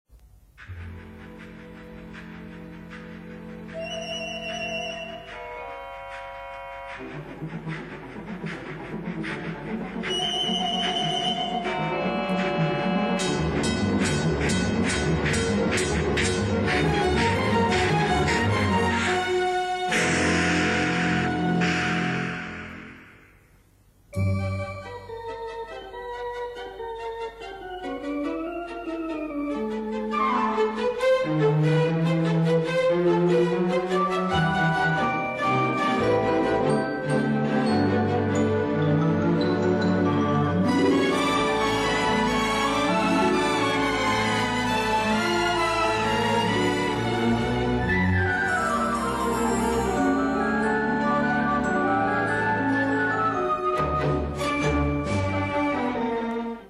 Suite in 4 Sätzen für Kleines Orchester